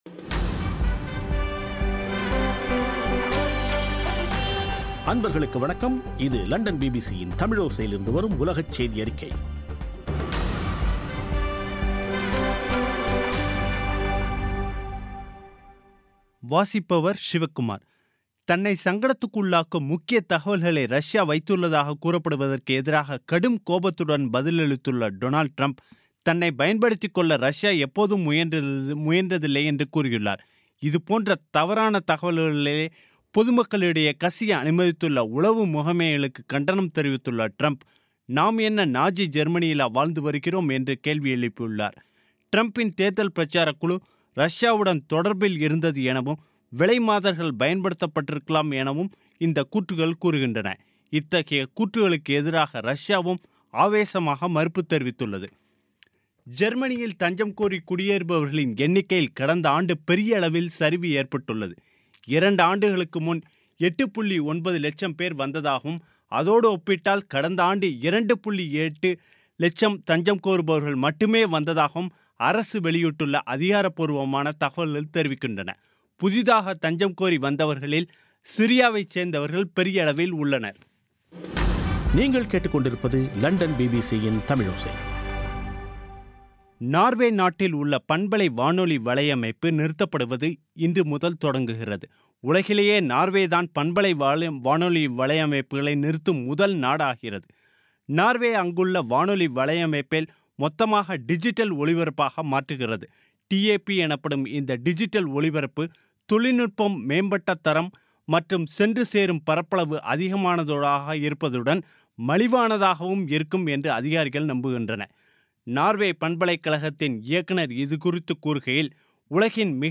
பிபிசி தமிழோசை செய்தியறிக்கை (11/01/2017)